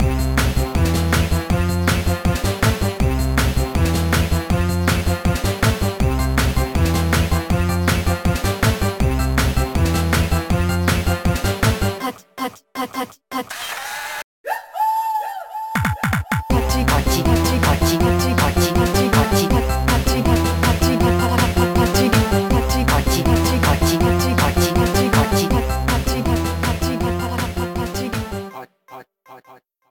Slideshow music